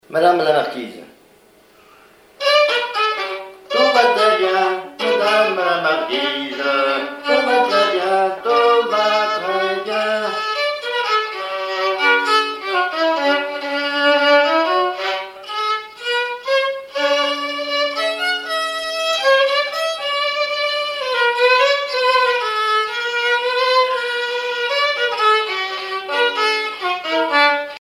violoneux, violon
danse : marche
Pièce musicale inédite